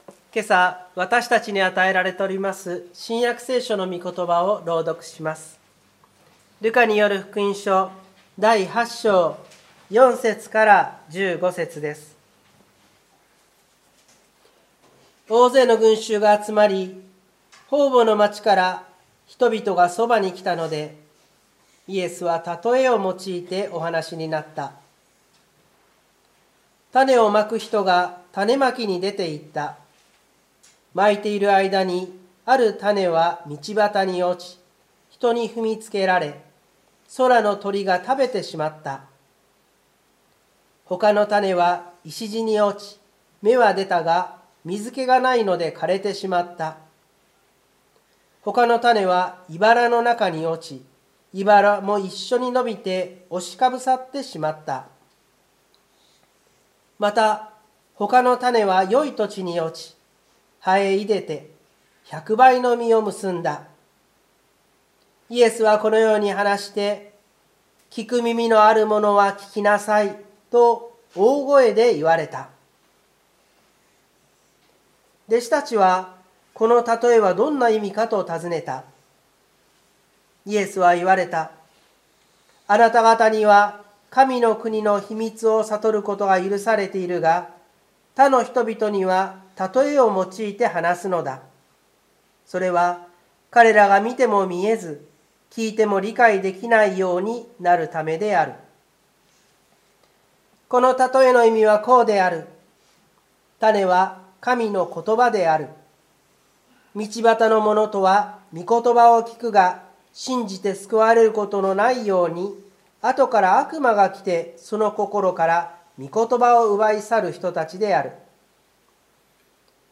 湖北台教会の礼拝説教アーカイブ。